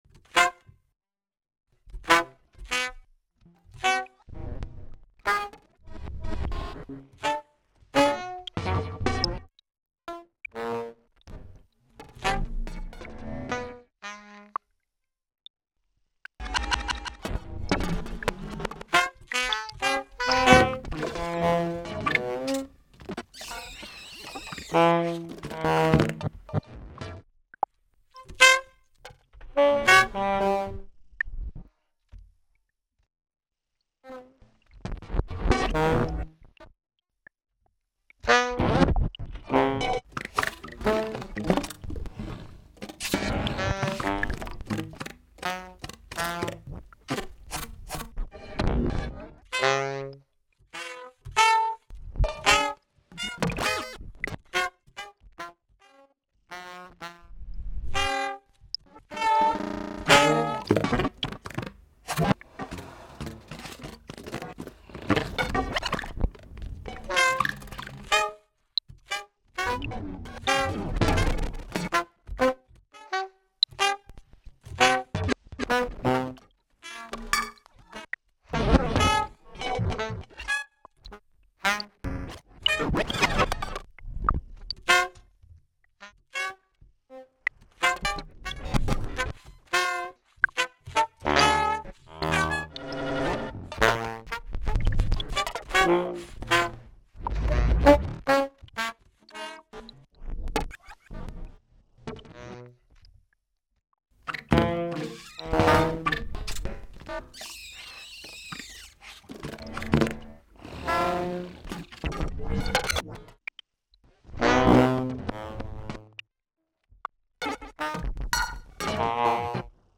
Electroacoustic composition/improvisation with short notes.
alto saxophone, samples and electronics.